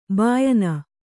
♪ bāyan